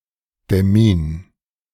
Demmin (German pronunciation: [dɛˈmiːn]